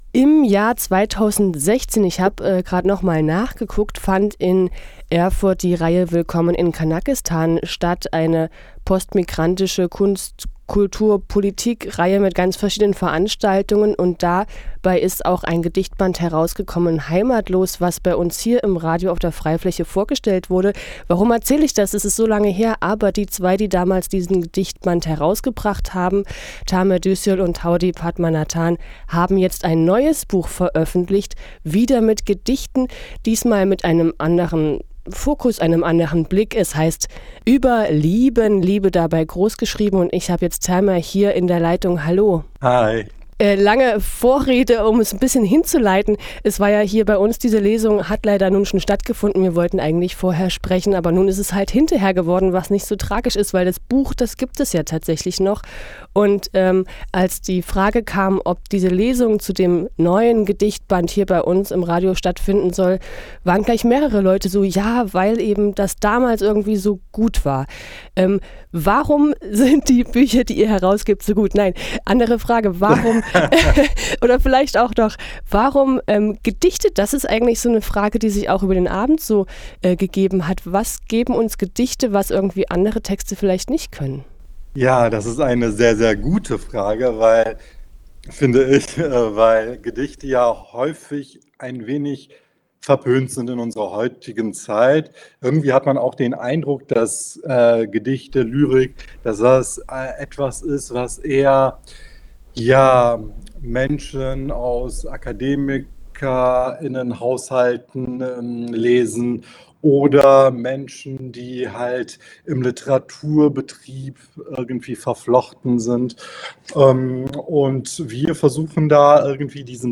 Mitschnitt + Interview | Lesung �überLIEBEn� | 25. November 2023 | F.R.E.I.-Fläche
Radio F.R.E.I. hat die Veranstaltung mitgeschnitten: Dein Browser kann kein HTML5-Audio.